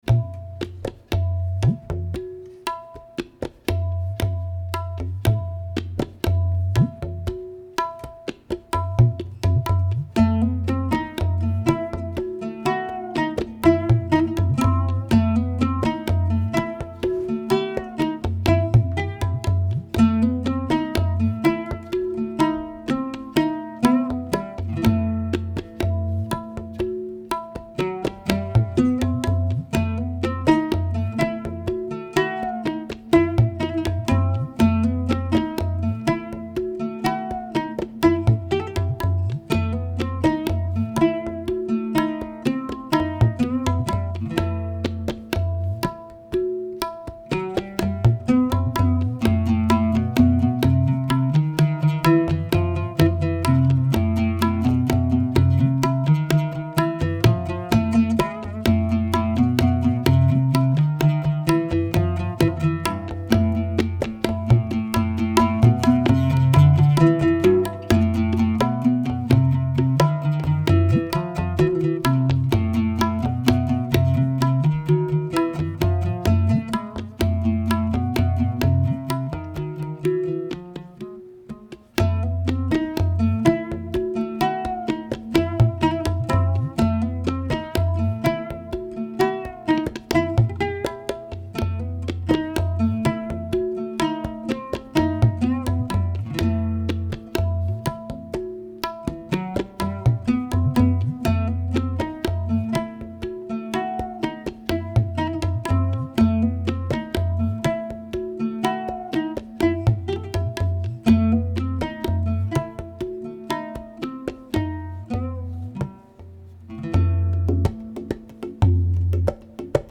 Live at Colby College